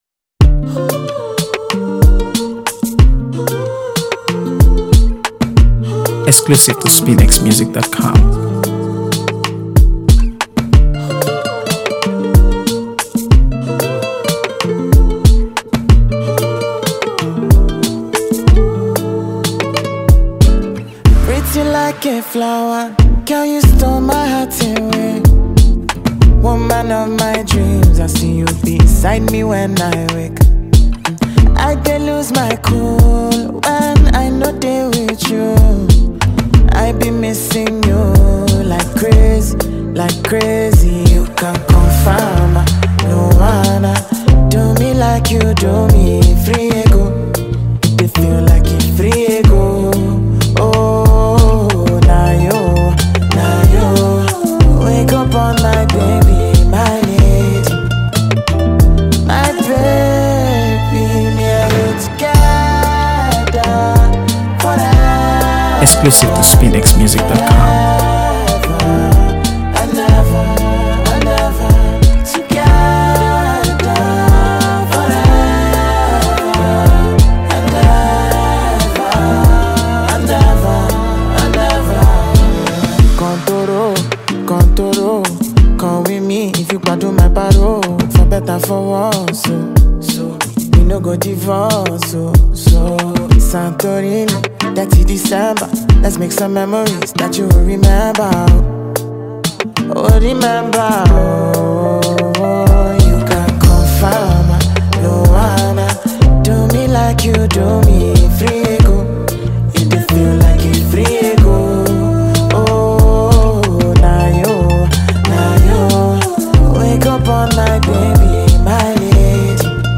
AfroBeats | AfroBeats songs
Blending smooth R&B textures with vibrant Afrobeats rhythms